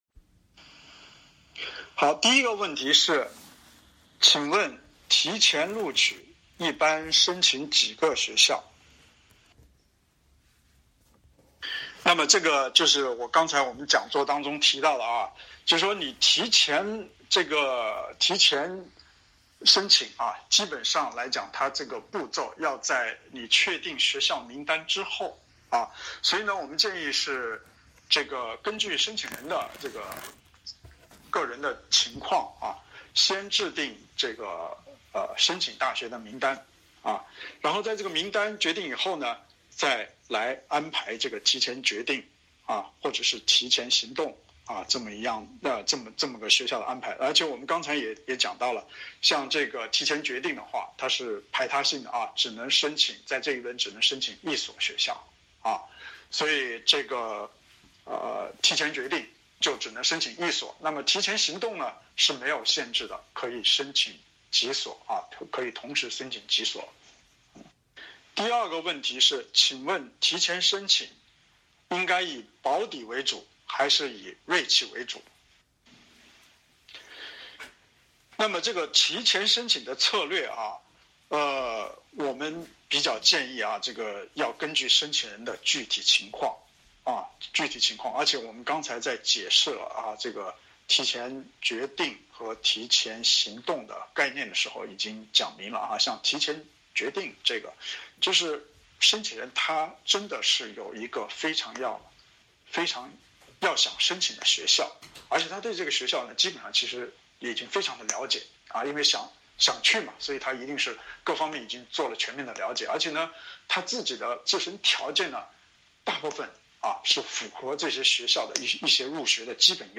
0524名门升学讲座实录---如何准备提前轮申请实录语音档出炉啦!! — 名门教育